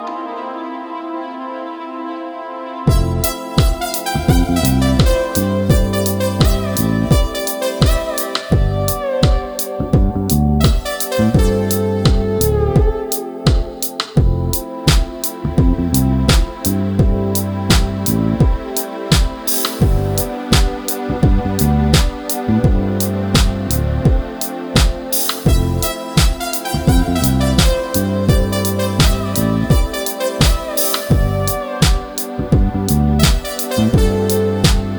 Жанр: Реггетон / Русские